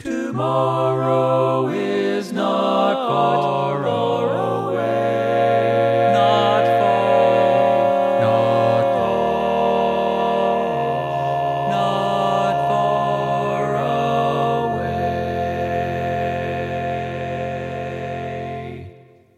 Key written in: F Major
How many parts: 4
Type: Barbershop
All Parts mix:
Learning tracks sung by